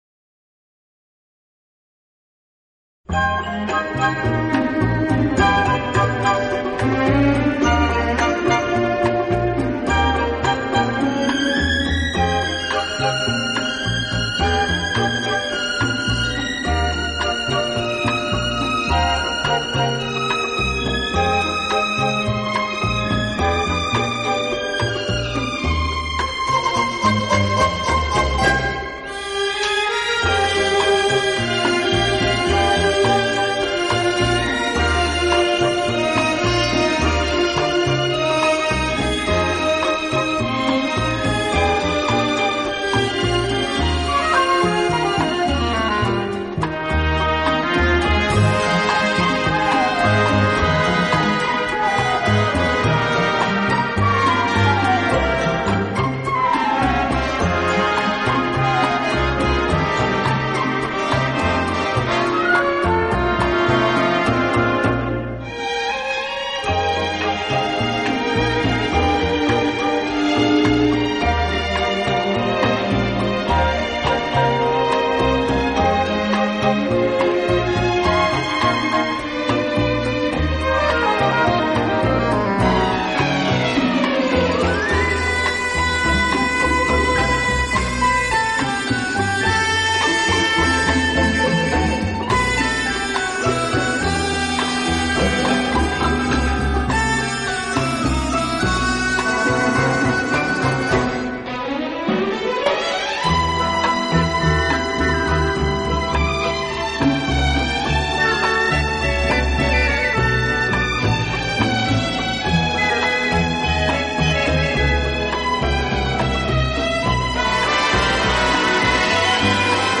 【轻音乐】
他的音乐具有强烈的感染力，既传统又不失轻音